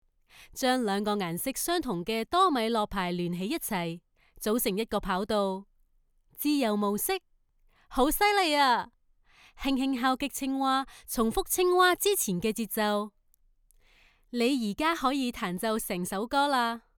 Female
Natural, engaging, authentic, with strong professional delivery
Video Games